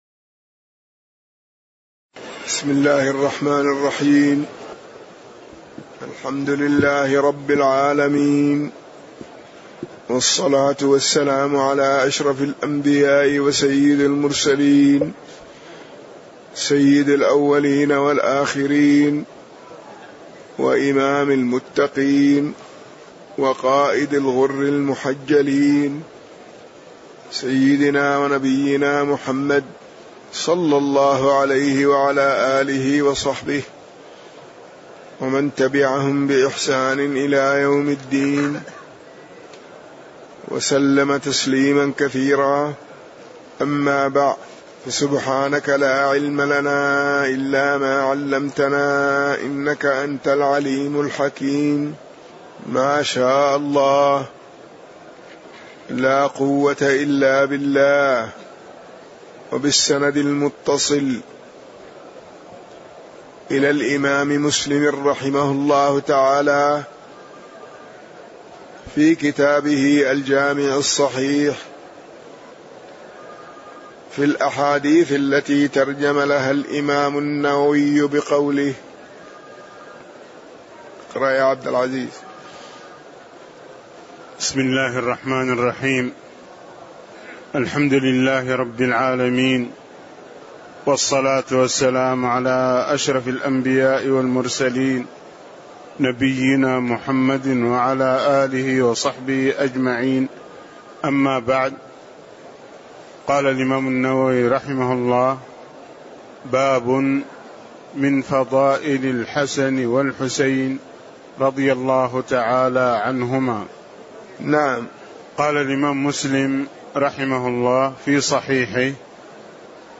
تاريخ النشر ١٧ شعبان ١٤٣٧ هـ المكان: المسجد النبوي الشيخ